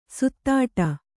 ♪ suttāṭa